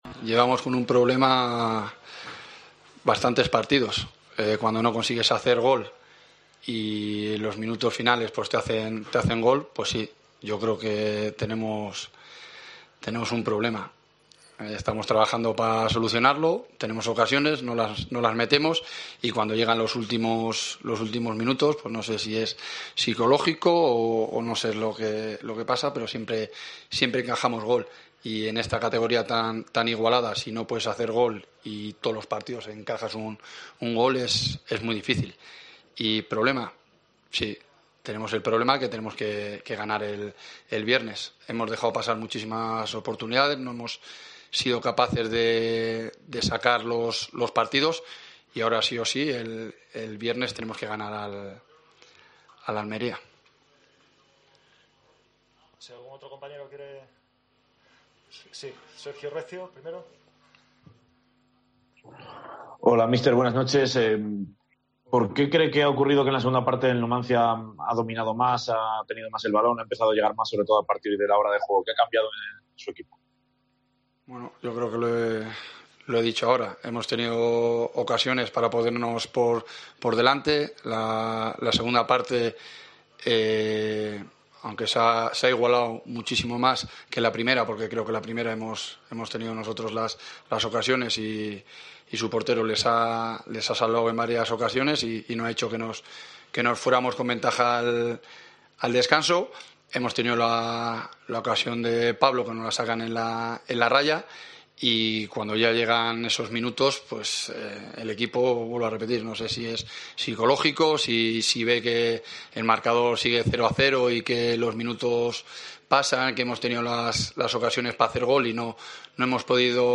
AUDIO: Escucha aquí el postpartido con declaraciones de Jon Pérez Bolo y Luis Carrión